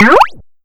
bounce.wav